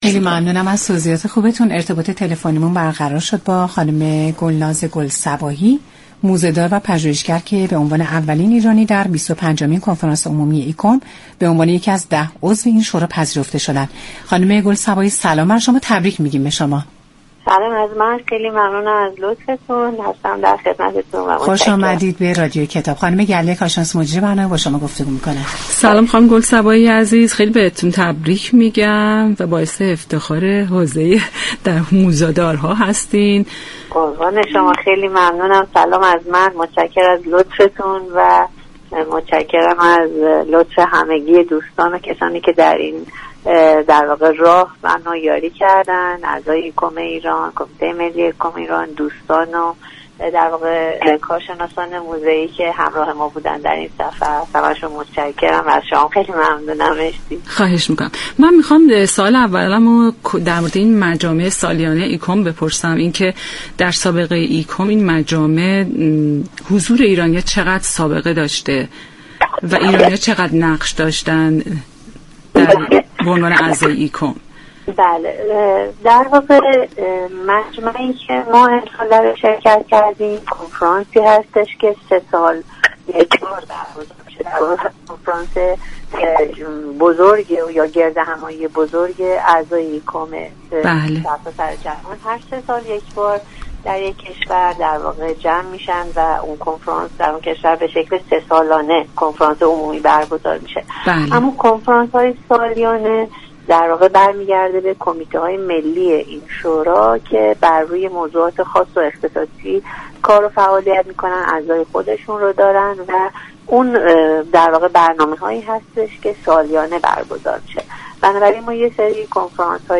گفتگو كرد